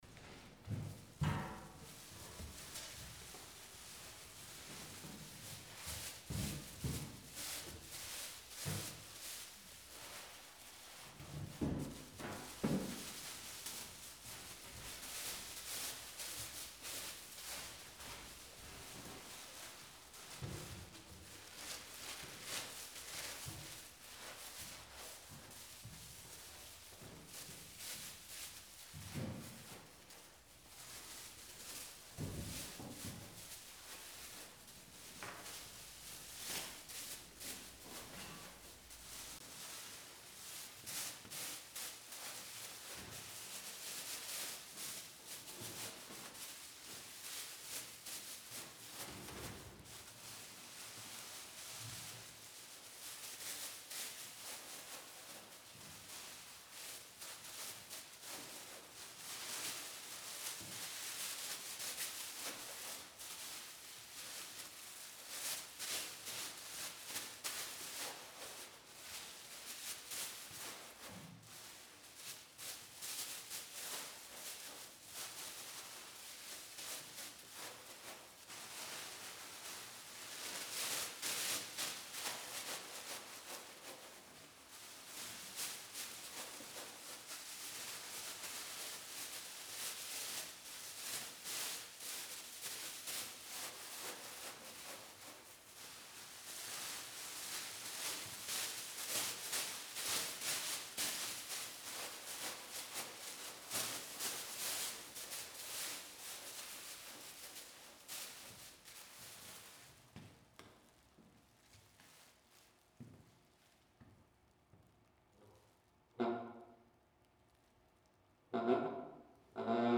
In Your Ear and Out Your Nose Car horns play the sounds of live voices and AM Talk Radio. sample sounds : click to play . . Issue Project Room.
In this piece I explore the space between on and off by activating the horns with other sound signals.
The result is a hybrid of two sounds that both rely primarily on rhythm and timbre to convey meaning.